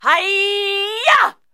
Bruitages sports combats 2
Bruitages et sons gratuits de sports : Combats